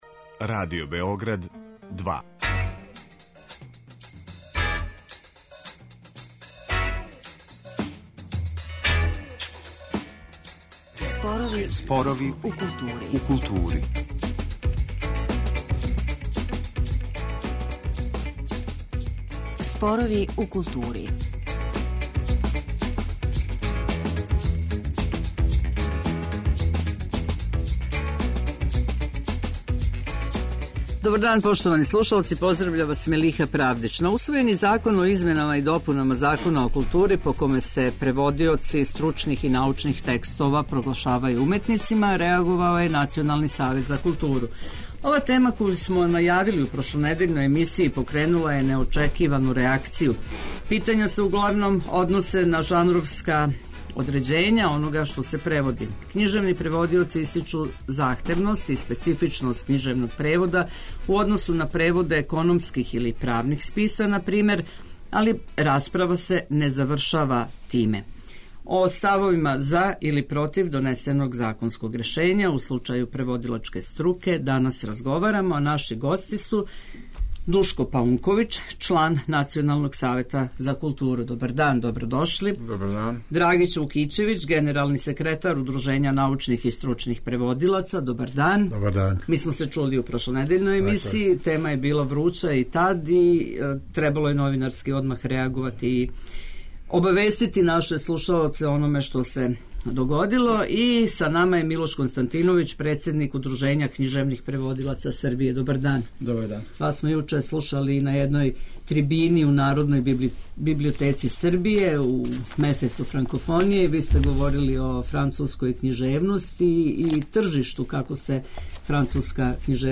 О ставовима "за" или "против", донесеног законског решења у случају преводилачке струке, данас разговарамо.